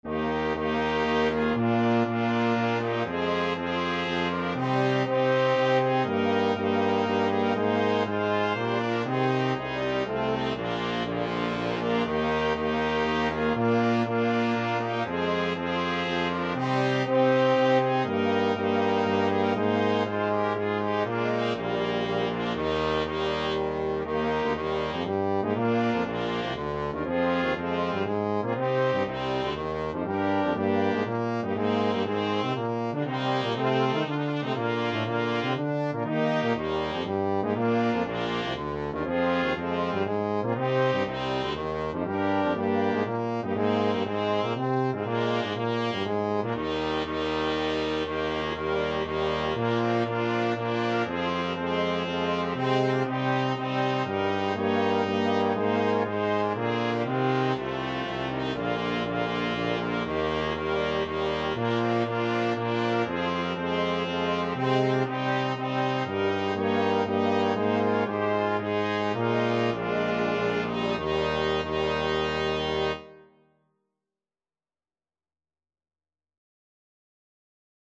Trumpet 1Trumpet 2French HornTromboneTuba
3/4 (View more 3/4 Music)
Sprightly = c. 120
Classical (View more Classical Brass Quintet Music)